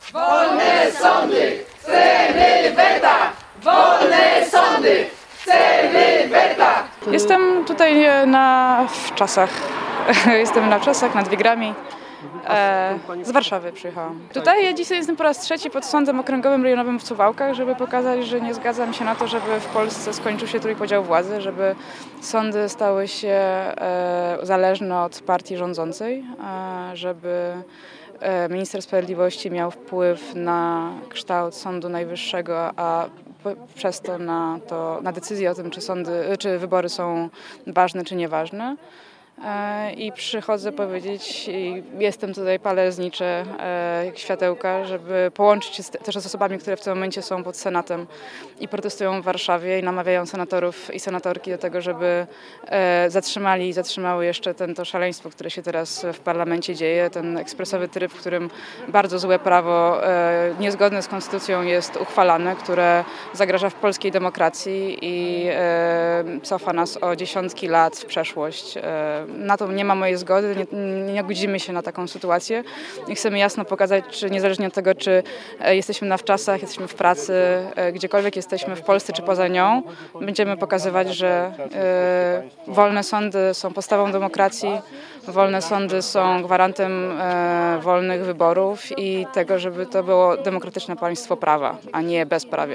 Około 30 osób protestowało dziś (21.:21) wieczorem przed siedzibą Sądu Okręgowego w Suwałkach.
protest-pod-sądem-w-suwałkach.mp3